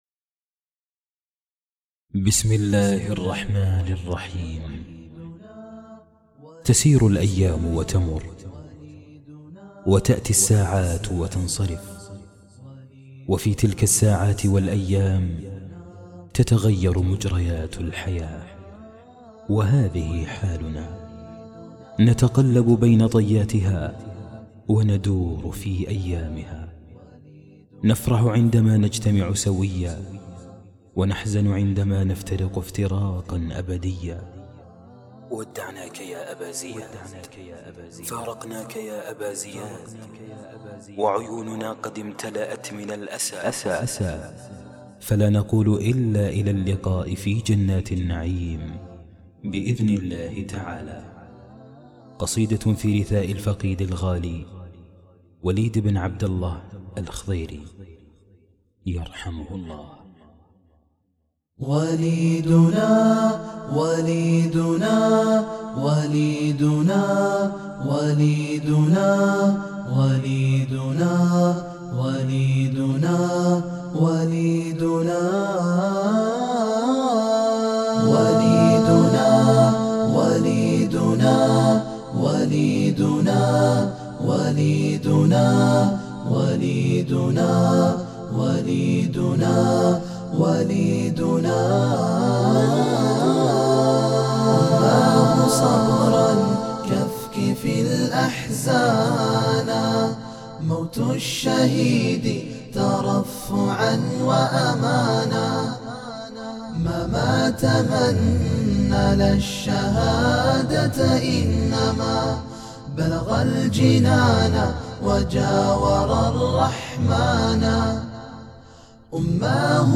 قصيدة رثاء في الفقيد الغالي.
الأداء والهندسة :